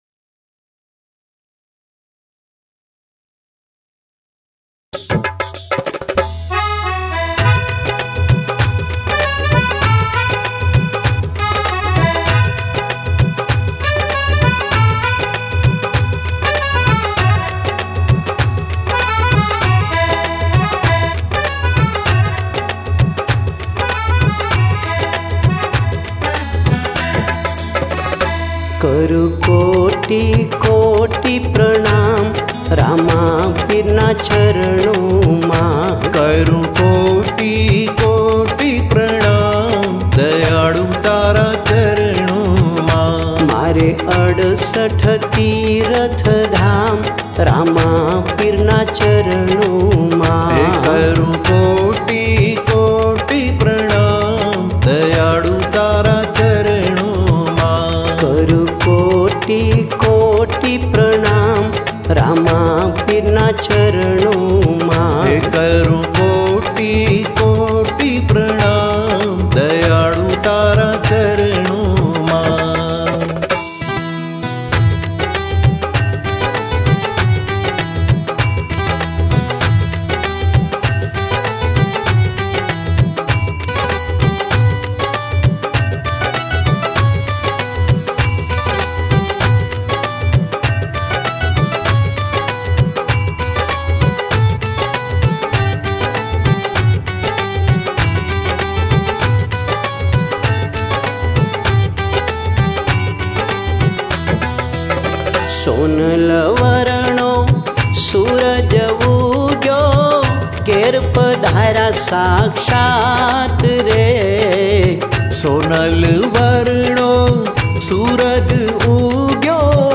Gujarati Bhajan